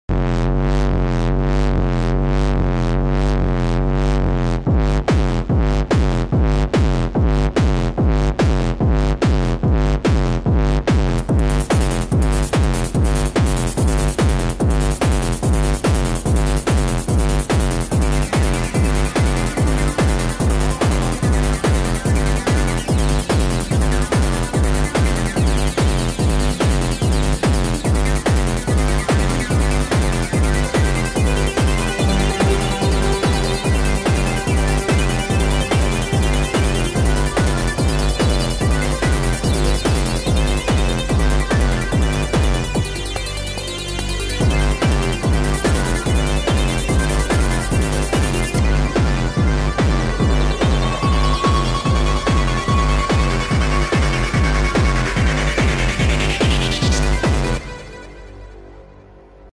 All sounds Made with Nord sound effects free download
All sounds Made with Nord Lead A1